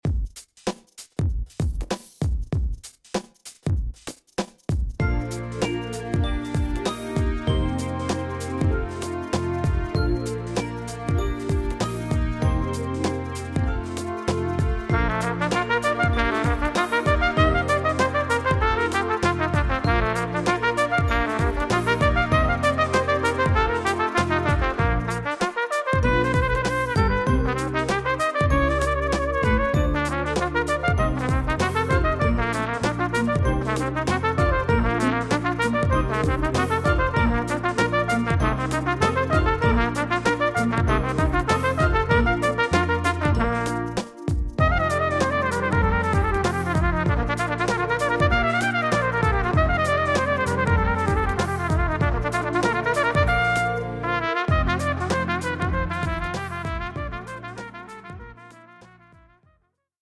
Etude pour Trompette ou cornet